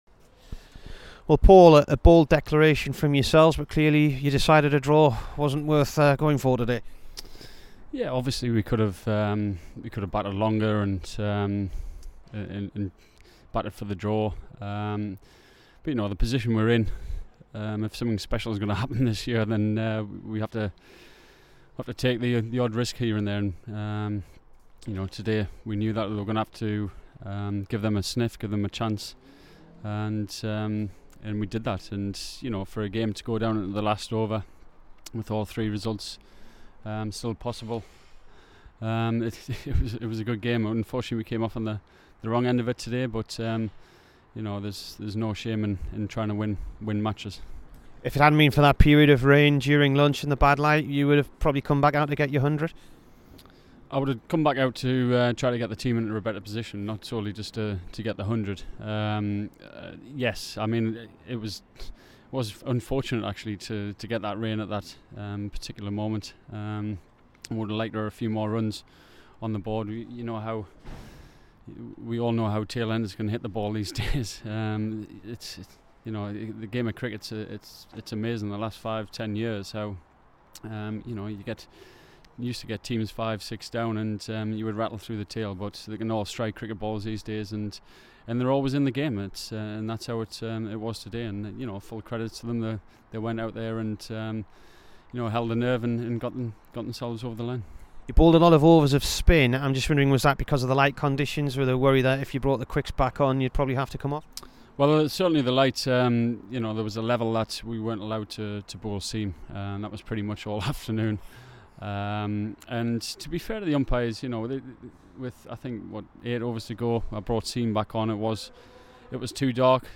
Here's the Durham skipper after the three wicket defeat to Glamorgan.